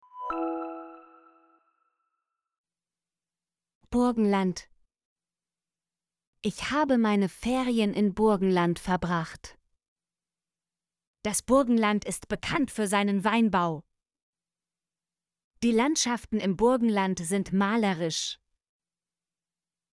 burgenland - Example Sentences & Pronunciation, German Frequency List